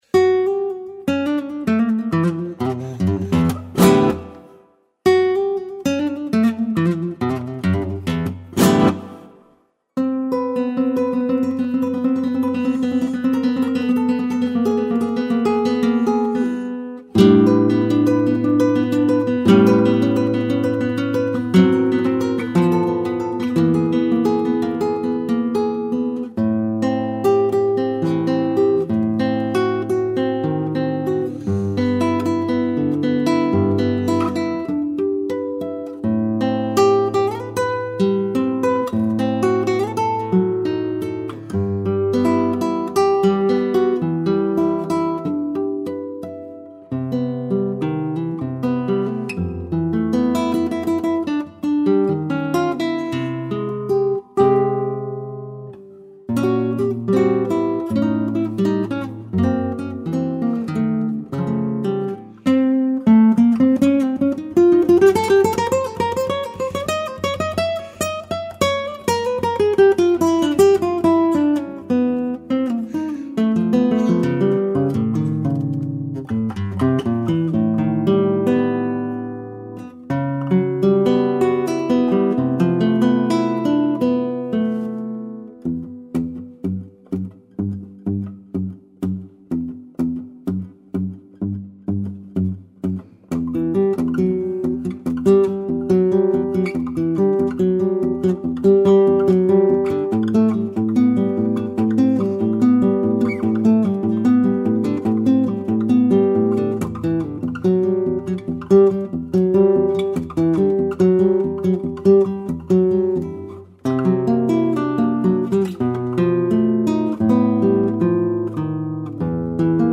Alates 3. taktist on lihtsad kahehäälsed laskuvad intervallid, mis kõlavad pisut kraapivalt (modernselt) ja mida illustreerib teine vaba keel.
Alates seitsmendast taktist on tegemist romantilise lõiguga, mis on üsna klassikaline akordide järgnevus: Am, A0, G6 koos variatsiooniga.
Takt 18, 19: eelnev muutub täistoongammaks, mis on kõlaliselt tühjem ja kõledam.
Alates taktist 22 bluusi meenutav süsteem, mis rütmiliselt sarnaneb mingil määral milonga rütmiga.